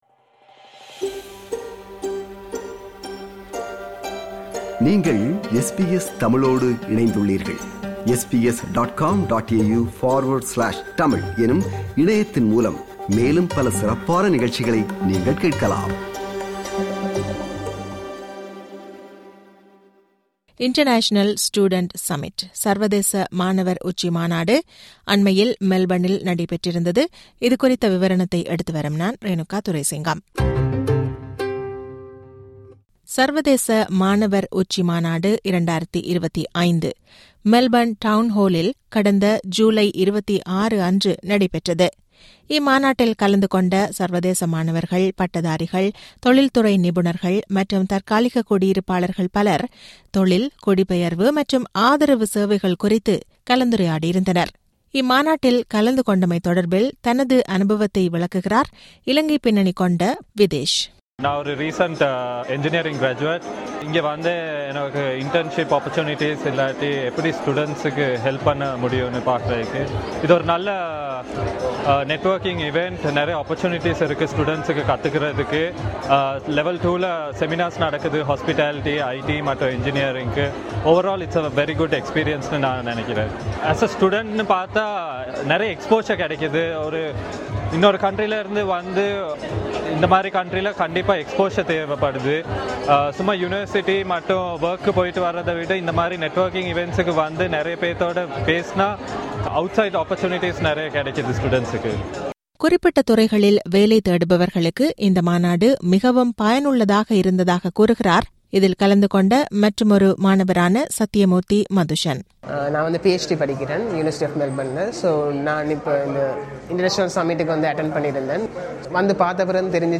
சர்வதேச மாணவர் உச்சி மாநாடு 2025 அண்மையில் மெல்பனில் இடம்பெற்றது. இதில் கலந்துகொண்ட மாணவர்கள் சிலரது கருத்துக்களுடன் விவரணமொன்றை முன்வைக்கிறார்